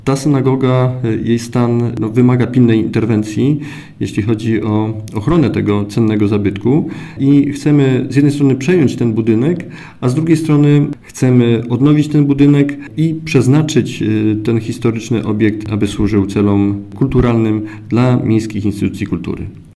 Mówi Maciej Małozięć, wiceburmistrz Dębicy.